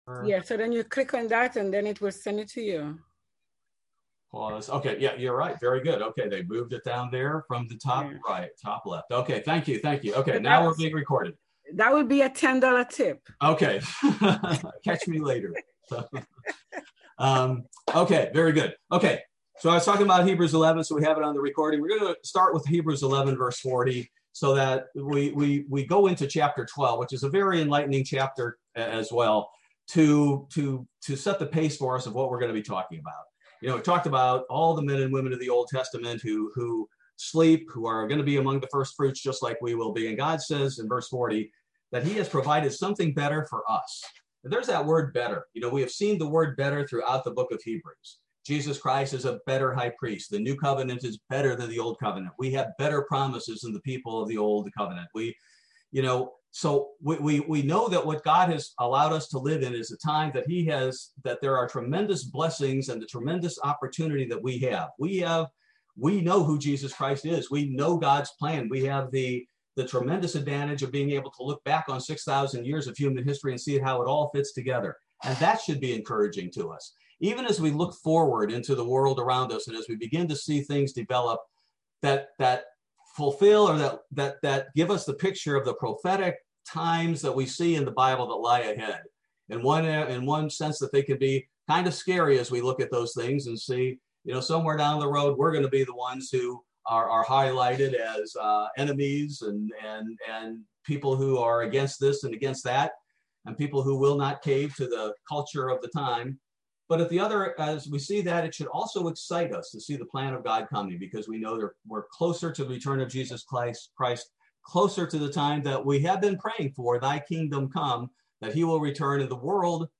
Bible Study - February 10, 2021